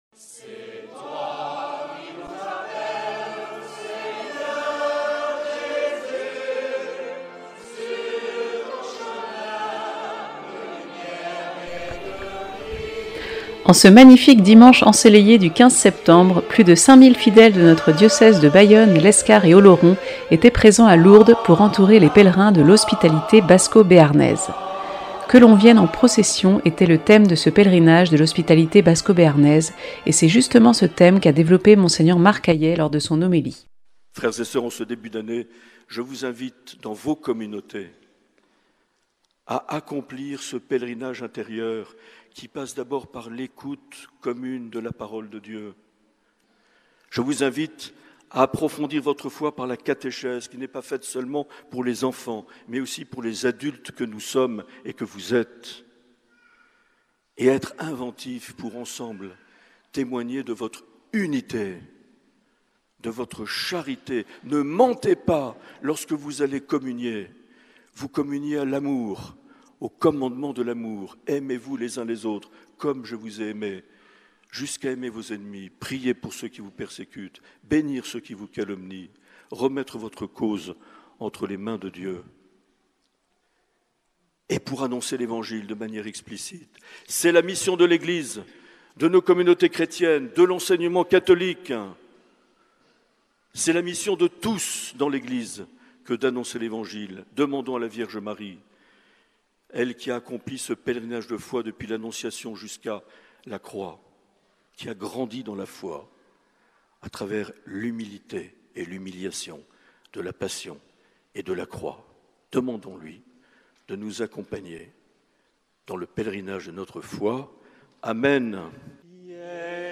Du 13 au 16 septembre, l’Hospitalité Basco-béarnaise a emmené environ 400 malades à Lourdes. Dimanche 15 septembre, plus de 5000 fidèles du diocèse se sont joints à eux pour la journée diocésaine. Reportage.